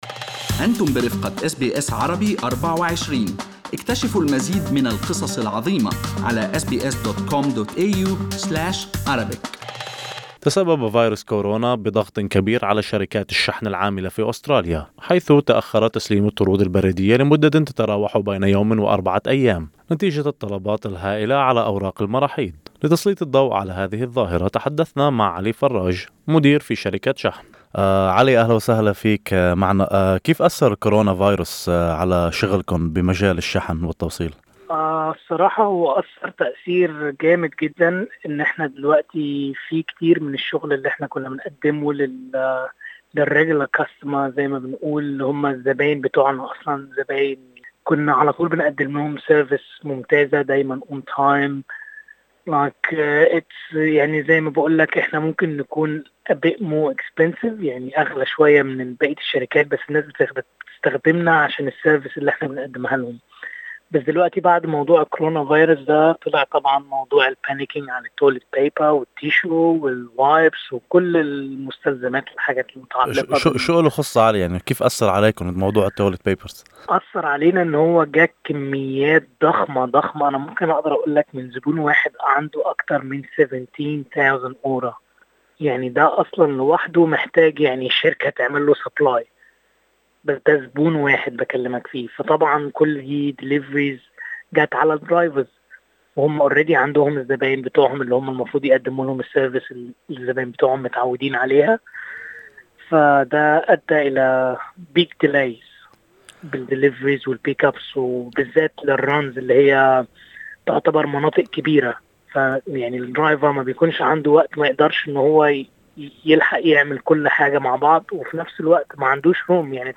في هذا اللقاء